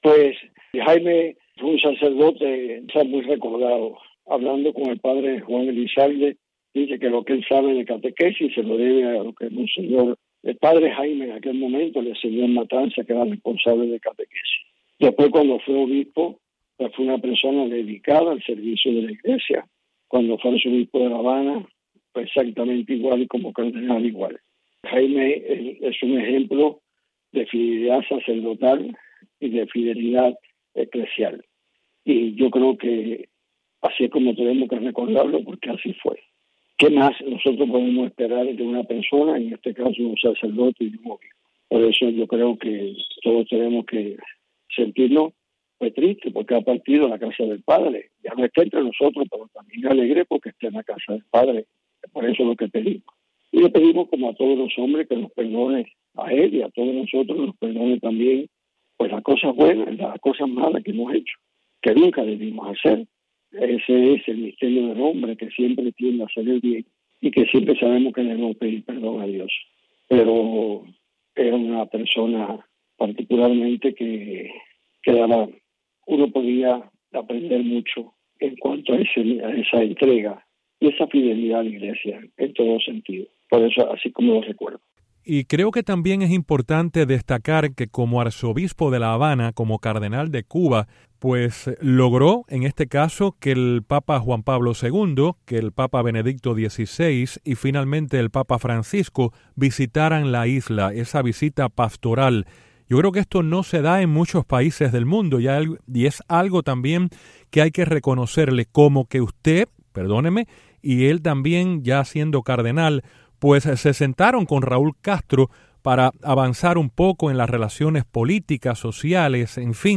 Declaraciones de Monseñor Dionisio García, arzobispo de Santiago de Cuba